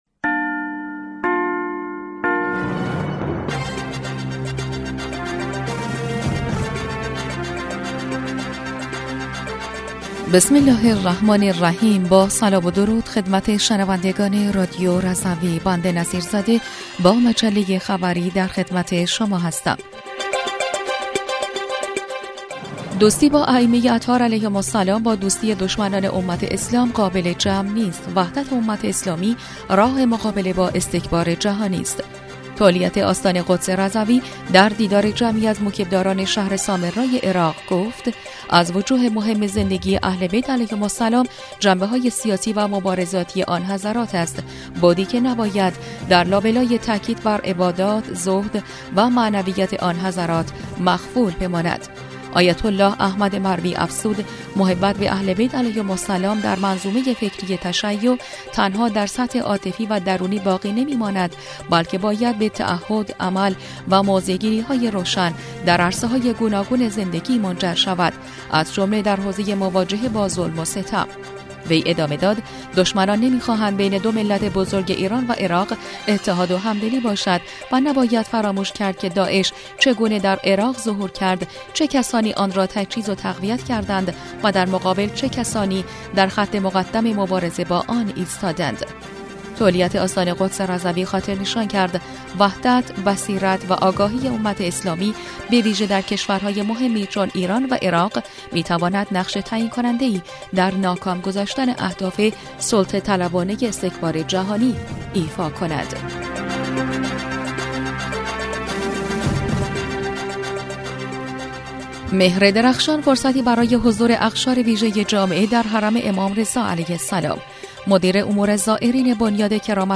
بسته خبری ۸ خردادماه رادیو رضوی/